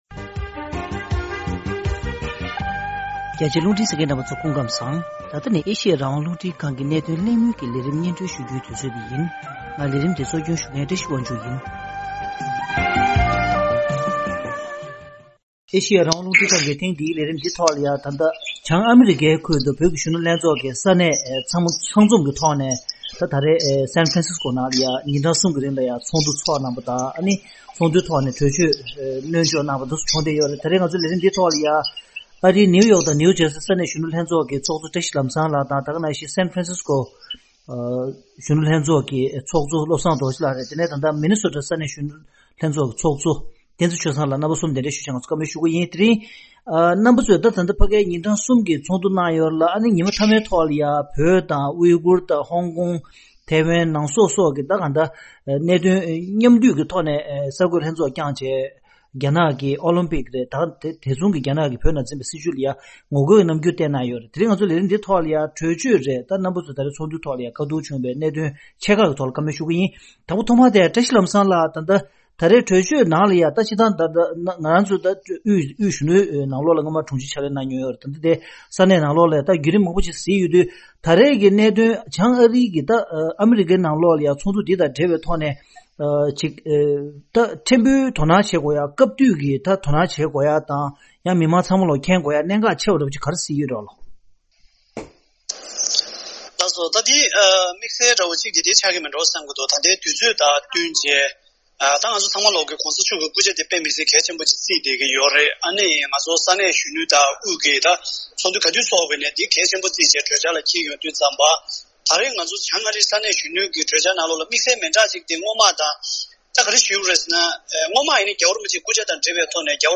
ཐེངས་འདིའི་གནད་དོན་གླེང་མོལ་གྱི་ལས་རིམ། ཉིན་གསུམ་གྱི་ཚོགས་འདུའི་ཐོག་ཚོགས་བཅར་བ་བྱང་ཨ་རིའི་ས་གནས་གཞོན་ནུའི་ལྷན་ཚོགས་ཀྱི་ཚོགས་གཙོ་གསུམ་གདན་ཞུས་ཀྱིས་བགྲོ་གླེང་ཞུས་པར་གསན་རོགས་ཞུ།